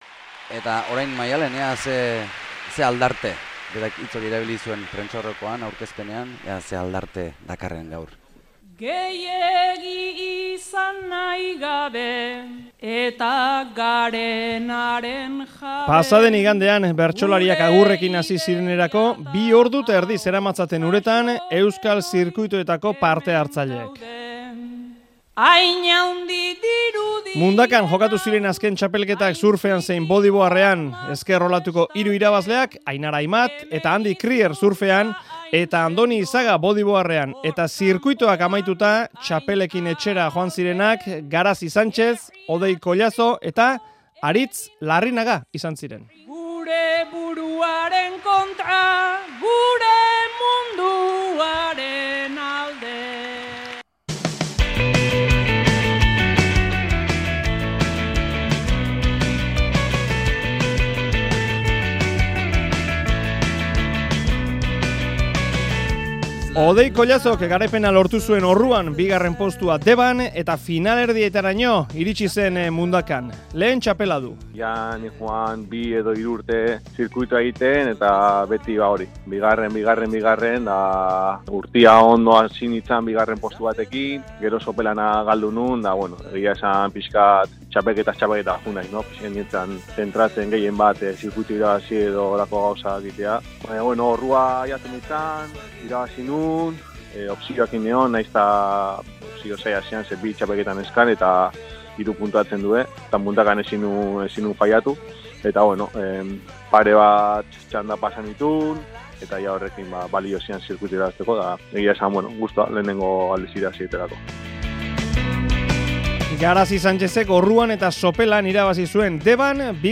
Audioa: Euskal zirkuitoetako hiru txapeldunekin erreportaia | Euskadi Irratia